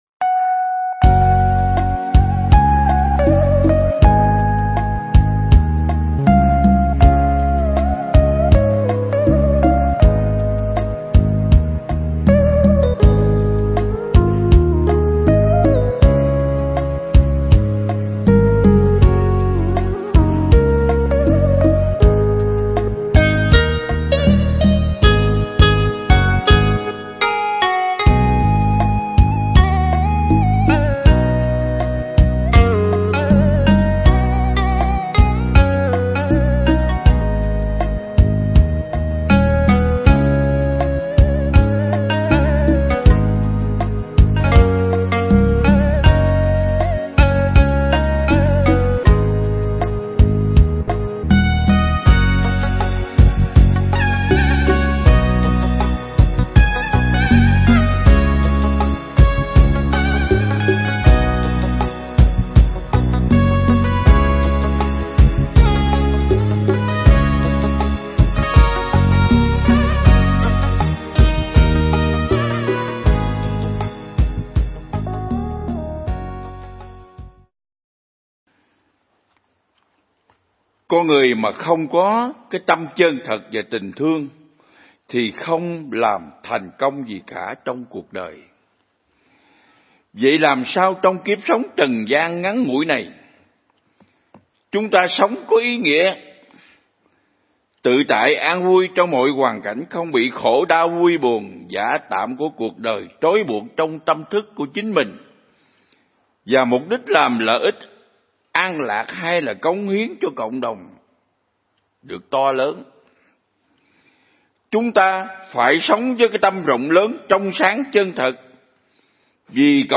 Pháp thoại
giảng tại Viện Nghiên Cứu Và Ứng Dụng Buddha Yoga Việt Nam (Hồ Tuyền Lâm, Đà Lạt)